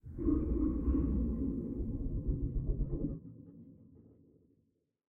Commotion12.ogg